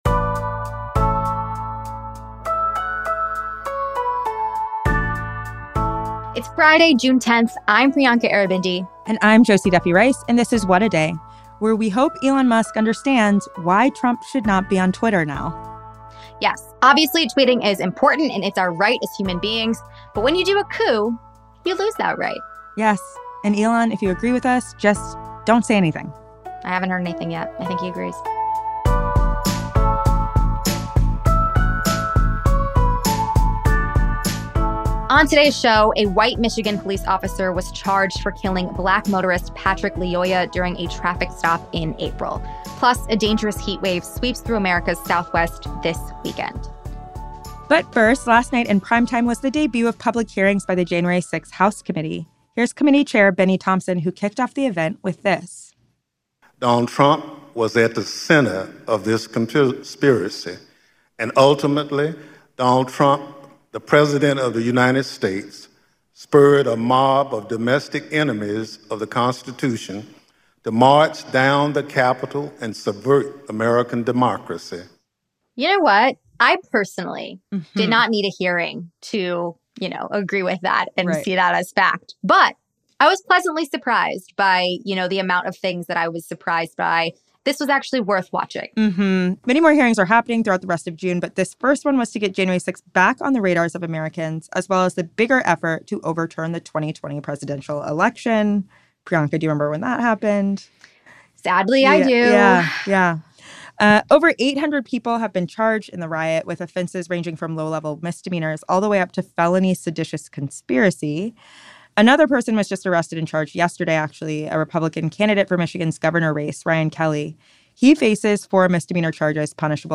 Last night was the first of several public hearings by the January 6th House committee, with the purpose to get the insurrection and the bigger effort to overturn the 2020 presidential election back on people’s radars. Congressman and committee member Adam Schiff, joined us before the hearing to explain what he hopes Americans will learn.